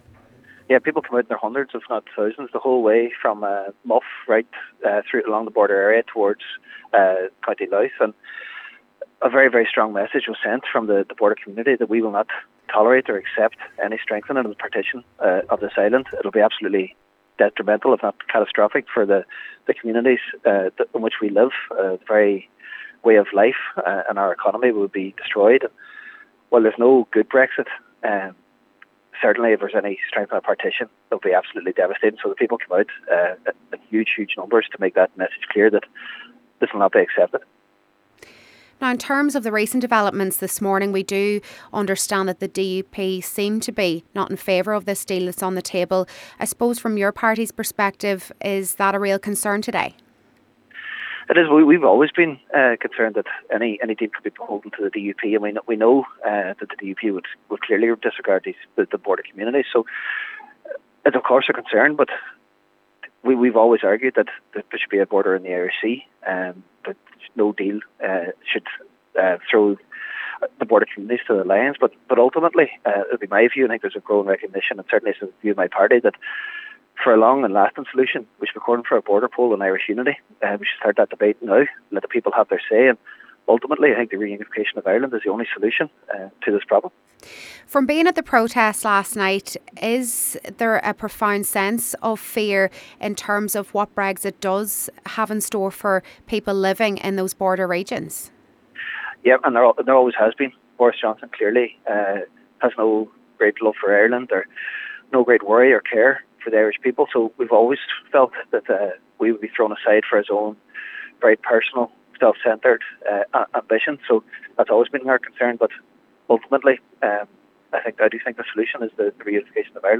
Cllr Jack Murray was at a well-attended protest in Bridgend.